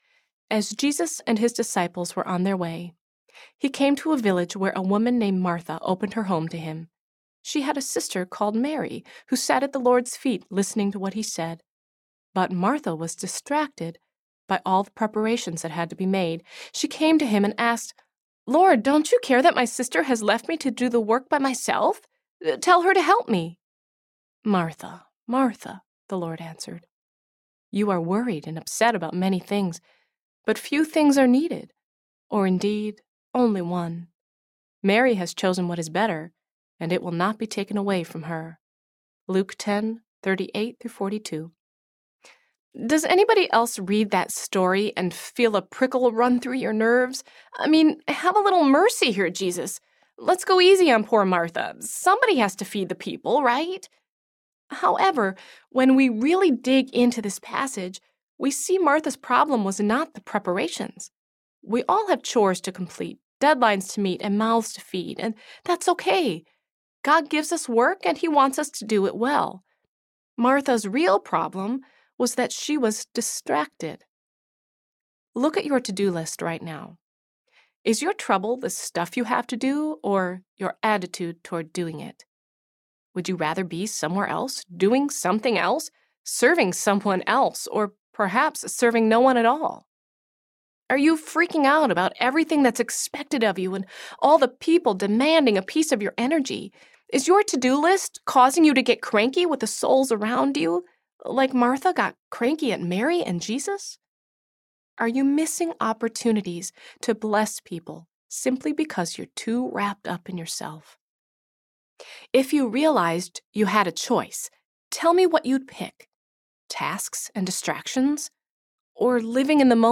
Generous Love Audiobook
Narrator